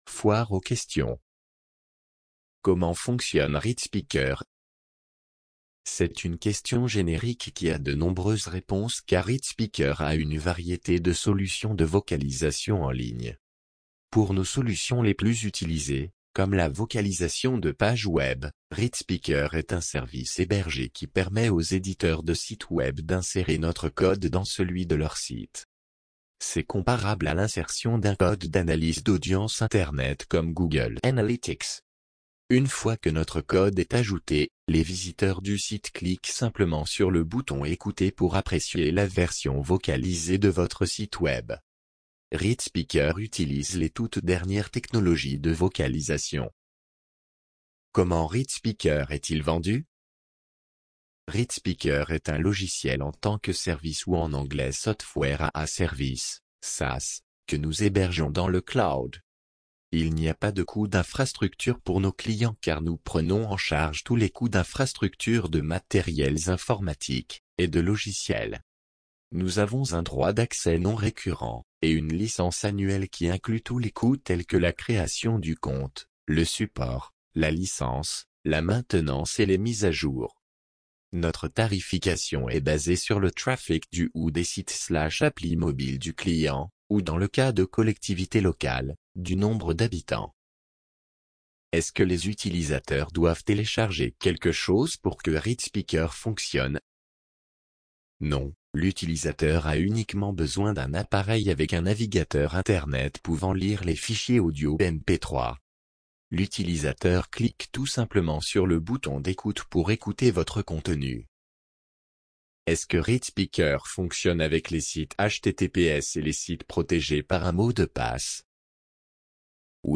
FAQ_FR - TTS.mp3